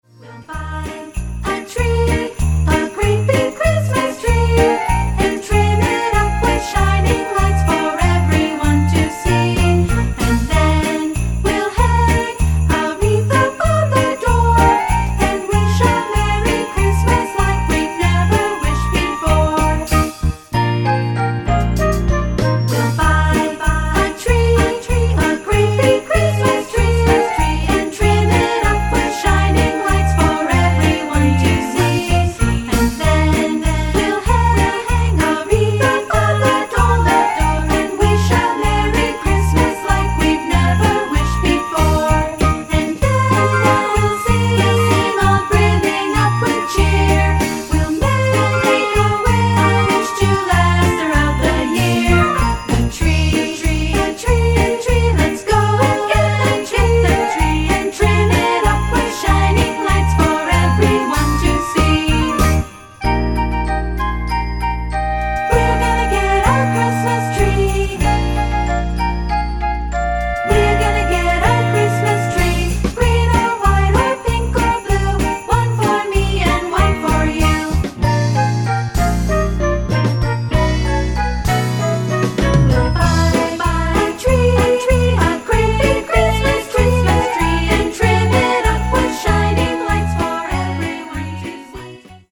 Recueil pour Chant/vocal/choeur - Choeur